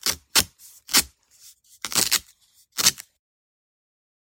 tape_pull1.wav